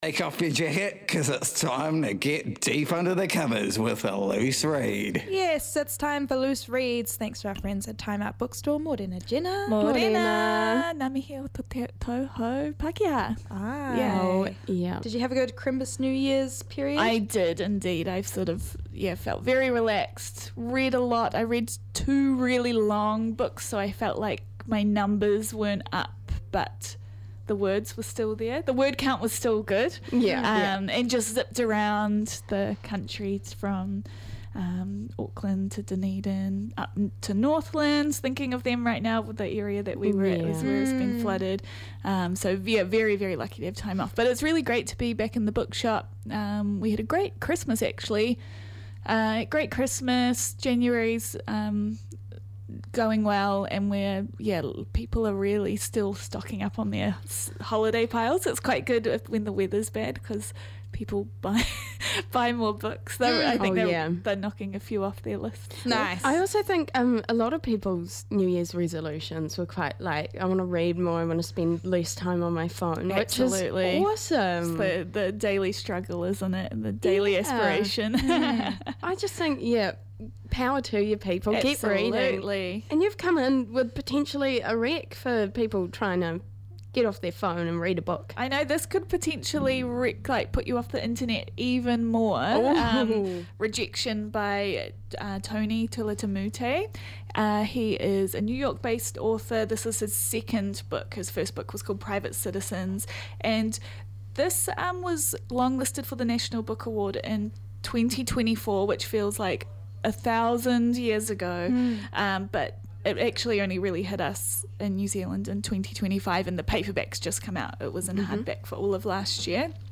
in the studio for another year of great reads